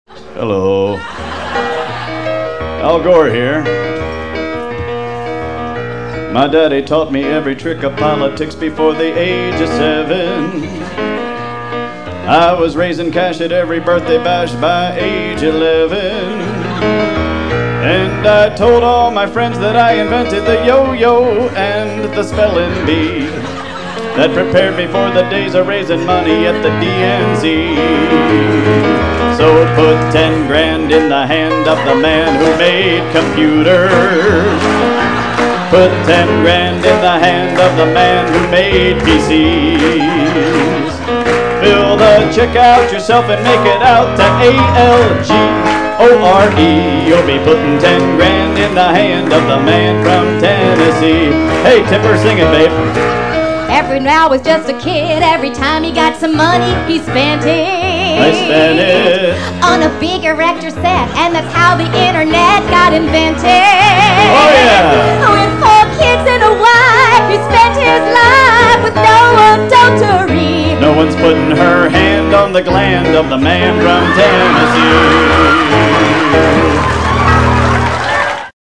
This one features a parody of Al Gore, singing "Put Ten Grand in the Hand."